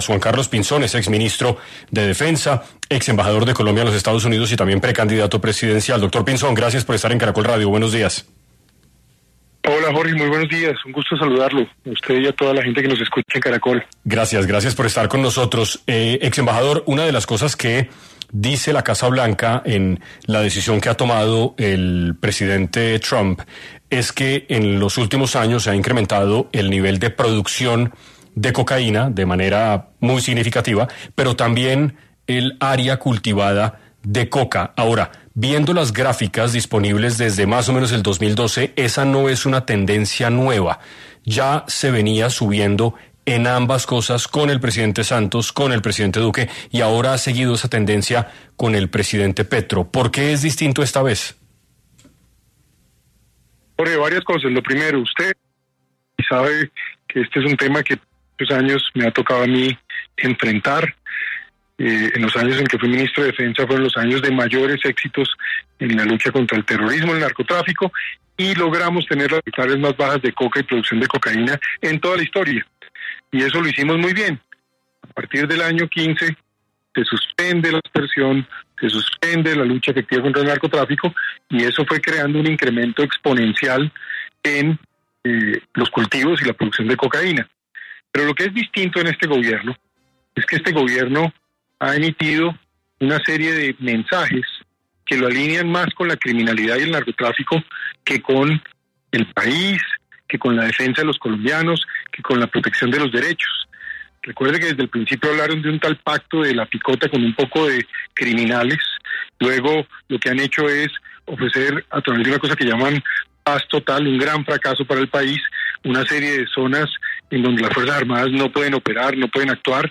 Juan Carlos Pinzón, exministro de defensa y ex-embajador de Colombia en los EEUU habla acerca de la reciente descertificación en 6 AM de Caracol Radio